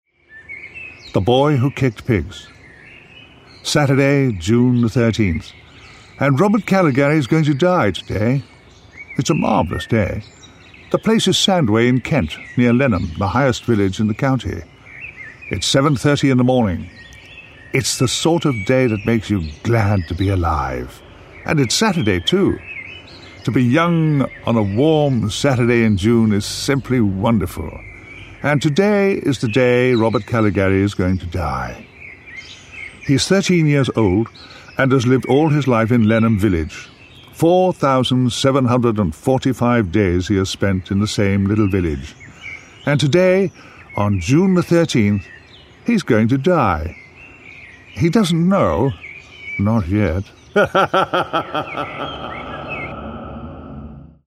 The Boy Who Kicked Pigs Audiobook Download
Written and read by Tom Baker
Tom Baker’s reading has the power and conviction to carry the listener through his story on an exhilarating wave of fantasy horror and black comedy, not for the faint hearted!
Tom originally made the recording as a download for SilkSound Books, but we are offering this new version with dramatic and comic sound effects, making it more powerful.